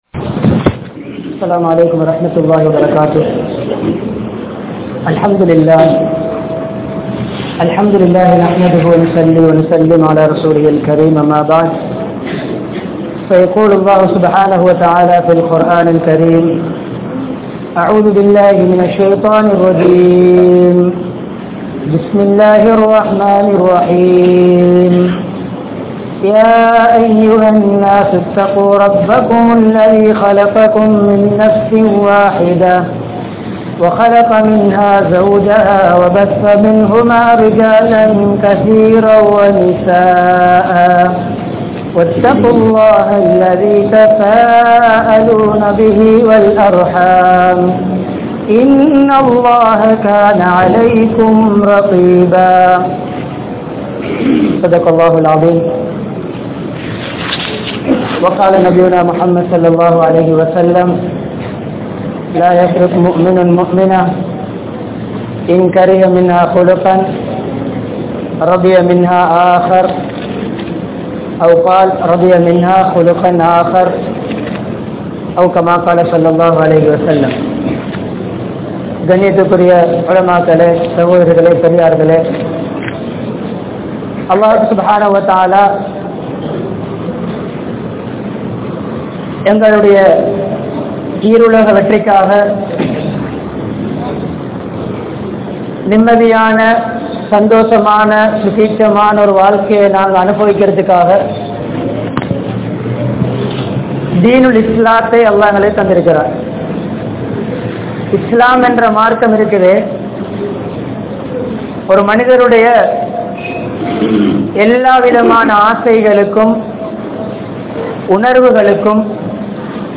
Illara Vaalkaiel Nimmathi Veanduma?? (இல்லற வாழ்க்கையில் நிம்மதி வேண்டுமா?) | Audio Bayans | All Ceylon Muslim Youth Community | Addalaichenai
Colombo 14, Layards Broadway, Jamiul Falah Jumua Masjidh